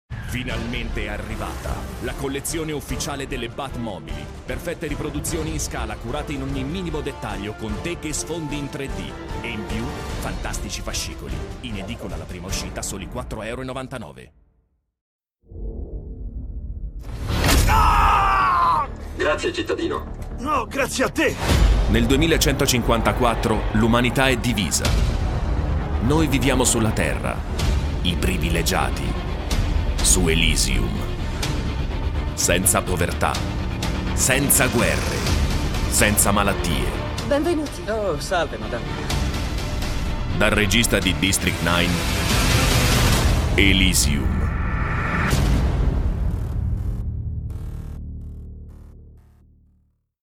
Voce baritonale e calda.
Sprechprobe: Werbung (Muttersprache):
Warm voice, precision, speed and efficacy.